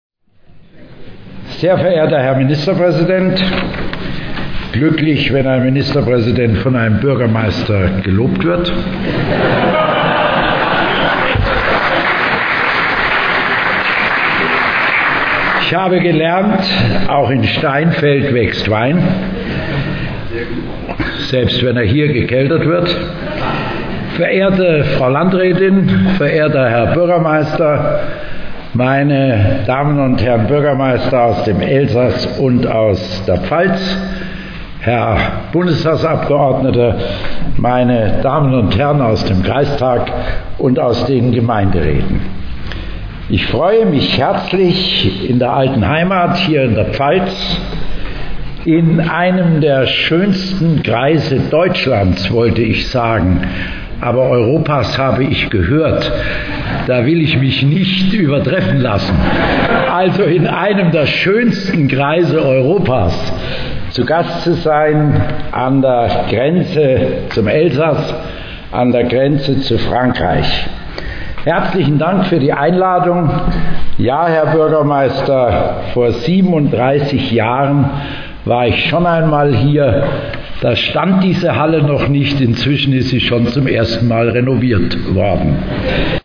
Festansprache
10 - Begrüßung - Prof.Dr.B.Vogel - 12. Grenzlandfest Kapsweyer.mp3